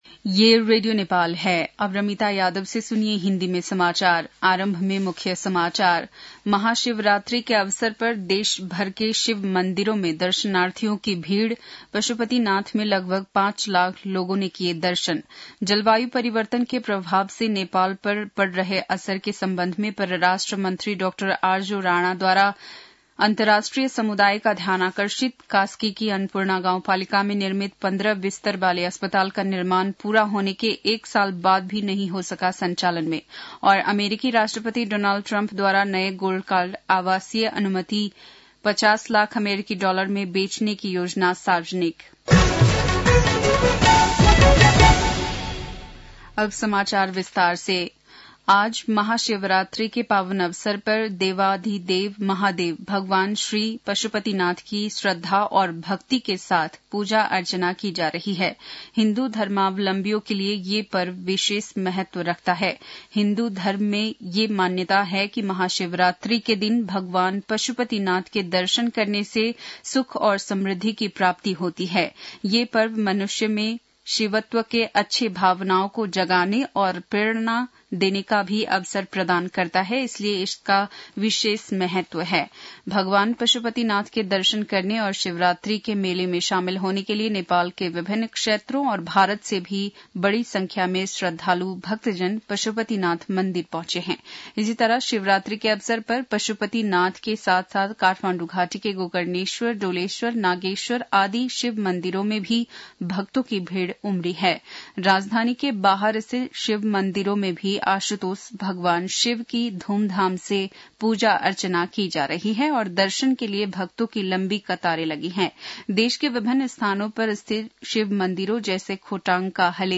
बेलुकी १० बजेको हिन्दी समाचार : १५ फागुन , २०८१